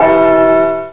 TWRCLOCK.WAV